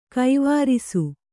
♪ kaivārisu